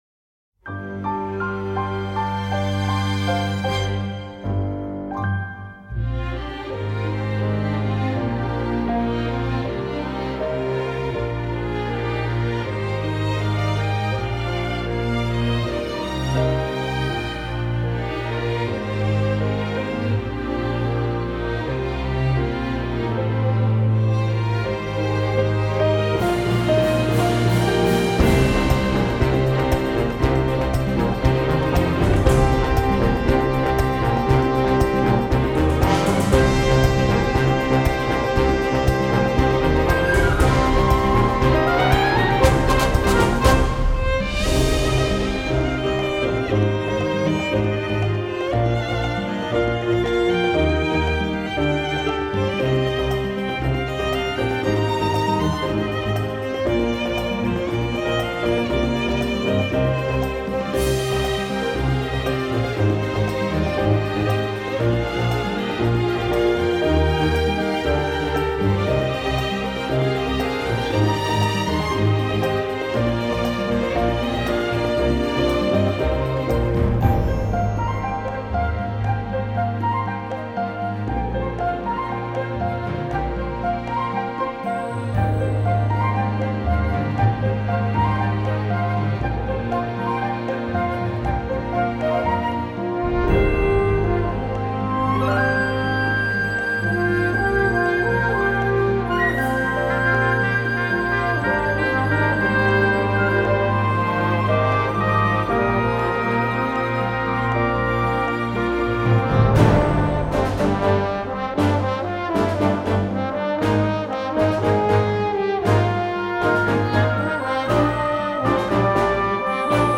Genre: Indie.